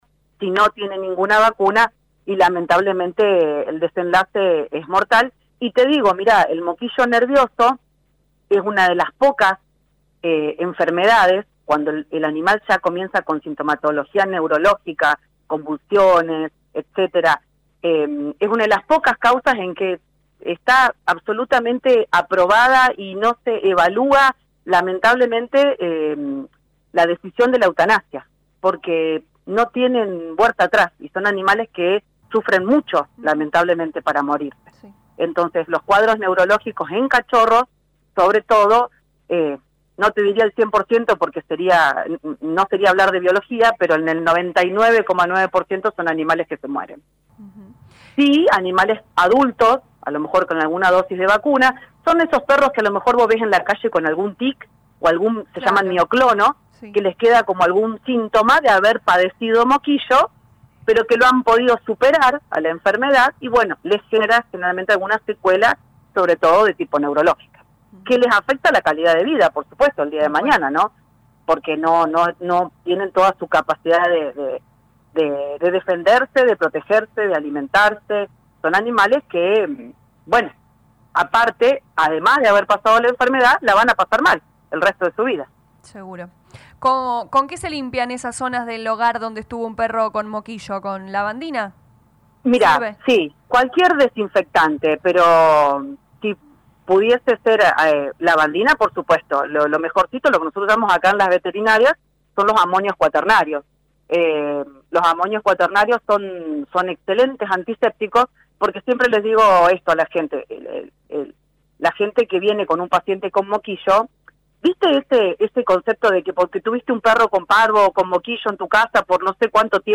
LA RADIO 102.9 FM dialogó con la médica veterinaria